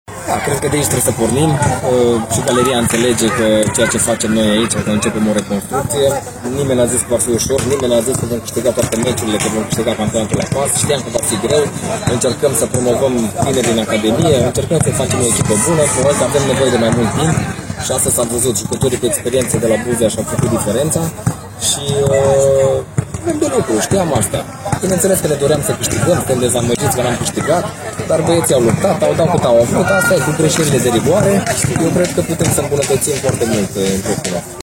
După jocul de la Lugoj, antrenorul Paul Codrea a declarat că reconstrucția la care s-a înhămat nu e deloc ușoară: